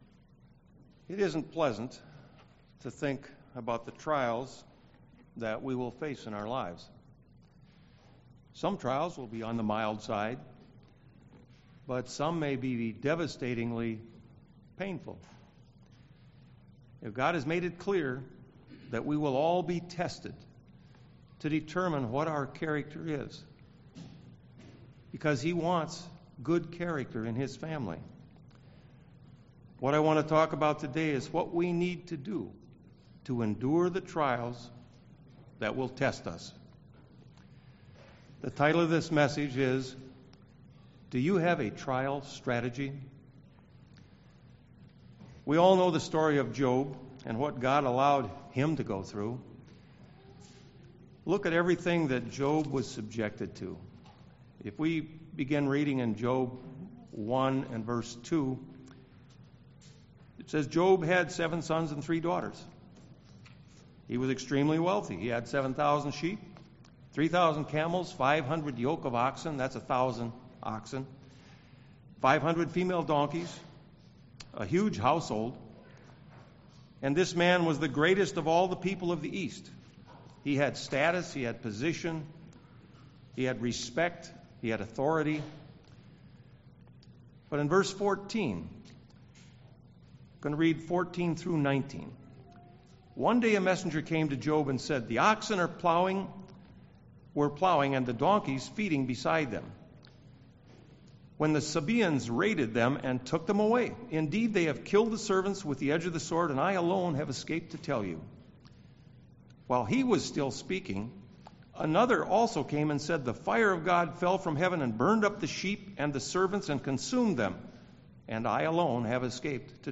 Given in Milwaukee, WI
Trials strategic plan strategic planning goal prepare prepared ready Job UCG Sermon Studying the bible?